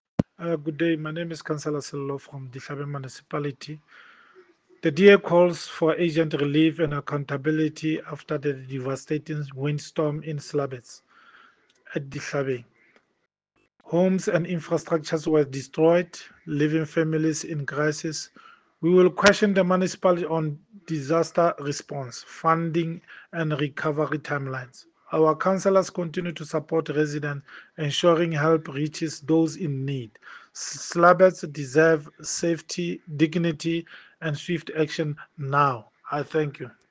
Sesotho soundbites by Cllr Sello Makoena.